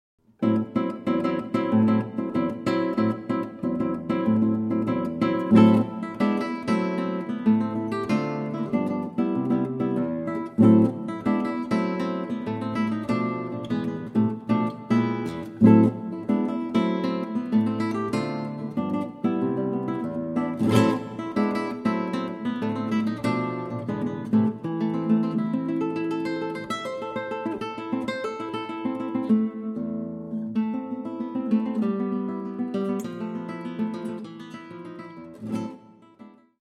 Solo Guitar
Trad. Afro-Cuban lullaby